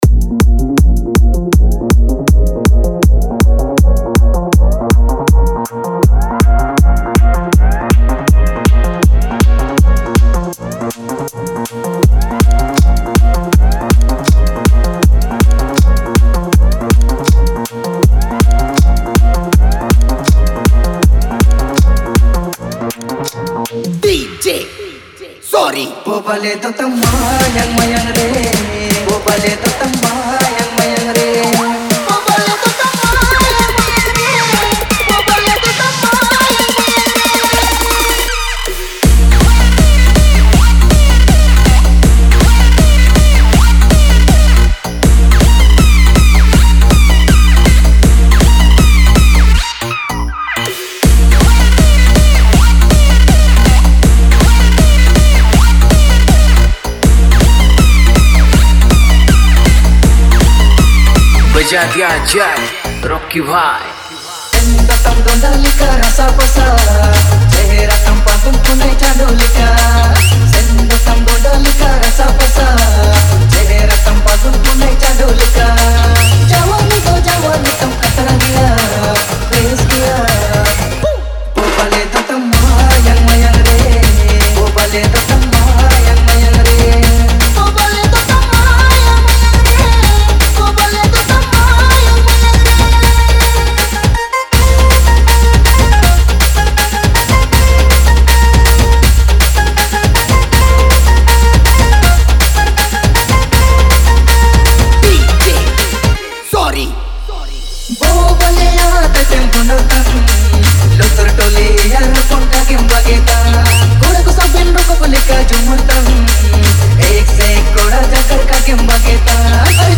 • Category: ODIA SINGLE REMIX